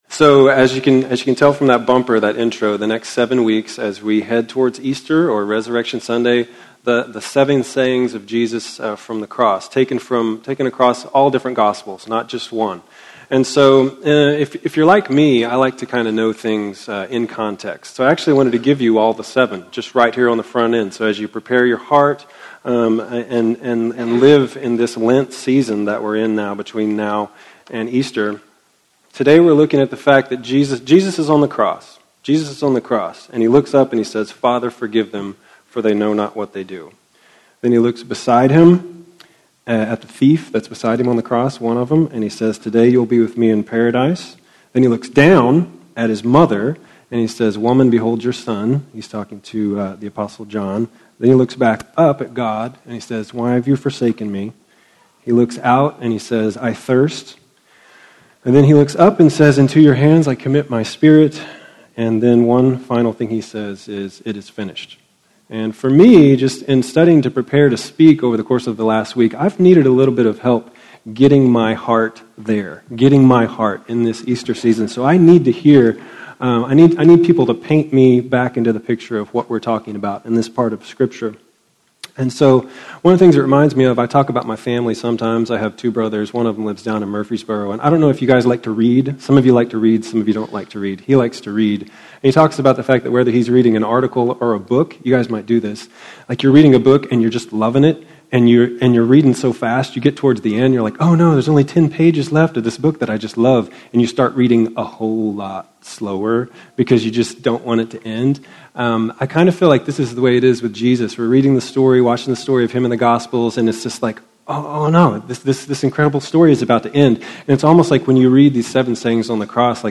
Father, Forgive Them - Sermon - Woodbine